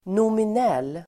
Ladda ner uttalet
Uttal: [nomin'el:]